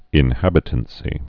(ĭn-hăbĭ-tən-sē)